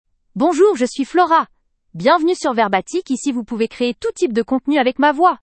FloraFemale French AI voice
Flora is a female AI voice for French (France).
Voice sample
Listen to Flora's female French voice.
Female
Flora delivers clear pronunciation with authentic France French intonation, making your content sound professionally produced.